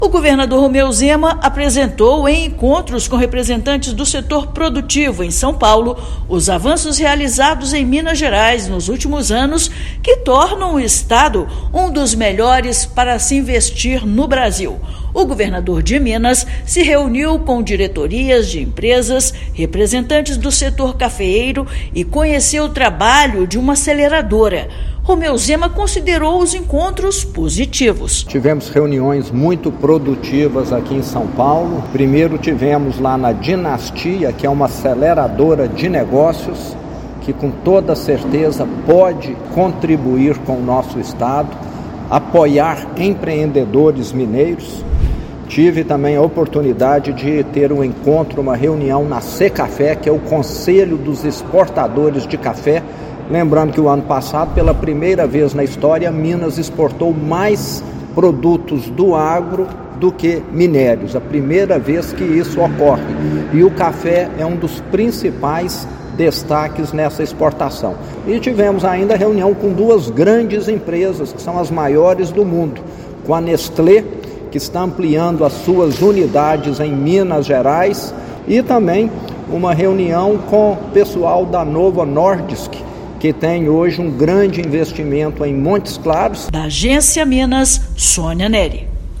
Durante agendas na capital paulista, chefe do Executivo mineiro mostrou para representantes do agro, indústria e empreendedores o cenário favorável para se investir no estado. Ouça matéria de rádio.